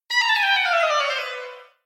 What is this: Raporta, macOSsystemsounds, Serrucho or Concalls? macOSsystemsounds